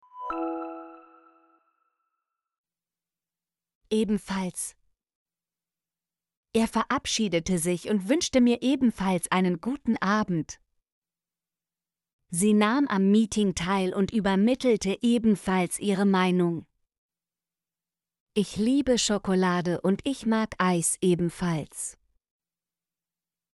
ebenfalls - Example Sentences & Pronunciation, German Frequency List